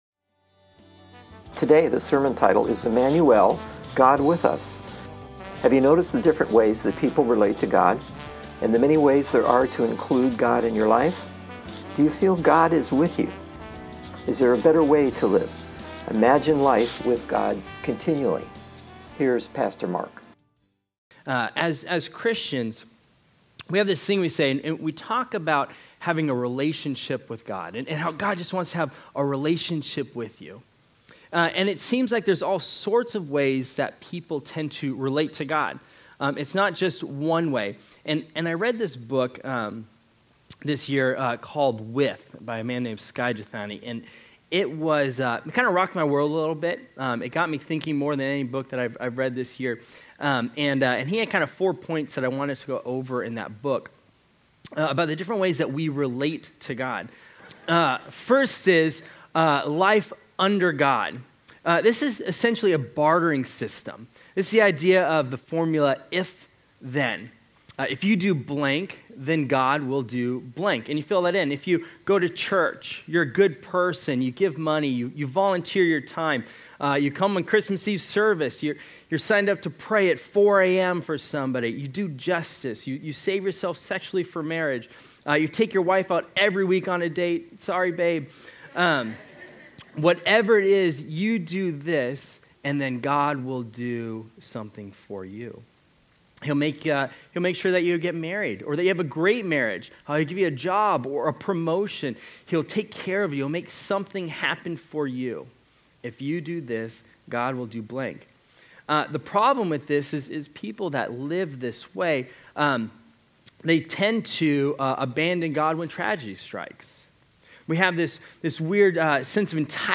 12/25/16 Sermon – Churches in Irvine, CA – Pacific Church of Irvine